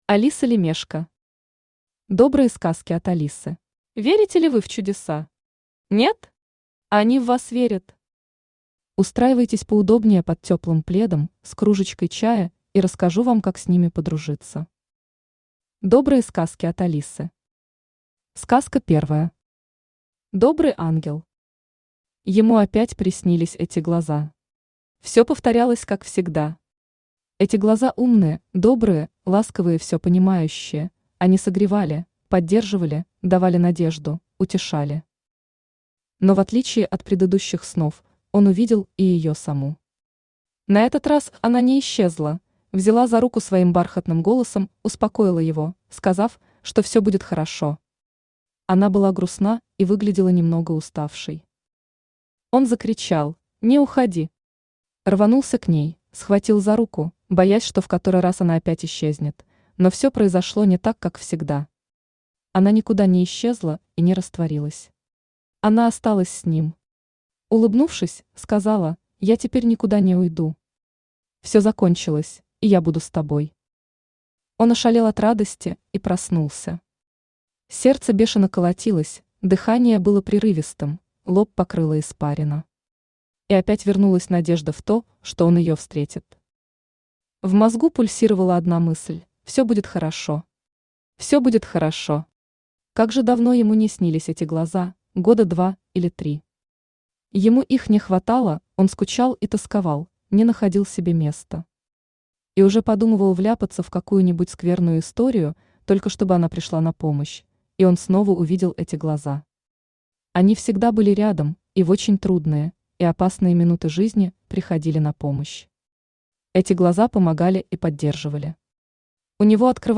Аудиокнига Добрые сказки от Алисы | Библиотека аудиокниг
Aудиокнига Добрые сказки от Алисы Автор Алиса Лемешко Читает аудиокнигу Авточтец ЛитРес.